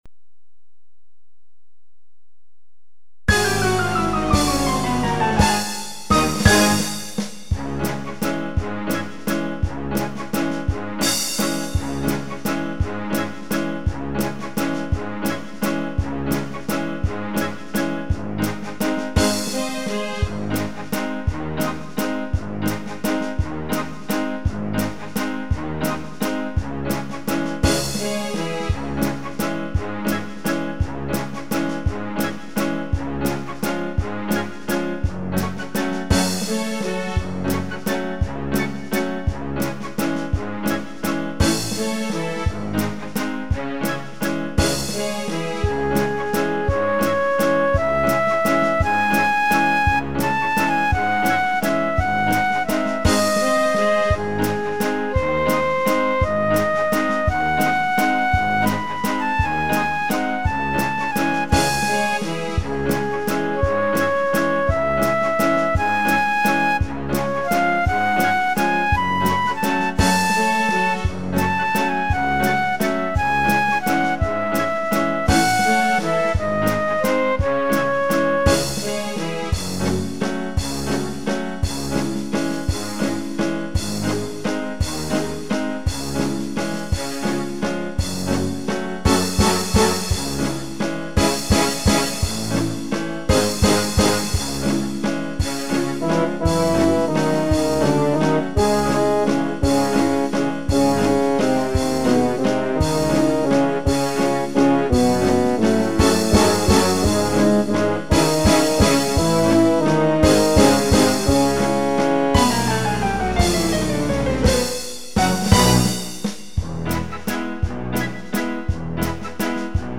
vals-sdiez-minus.mp3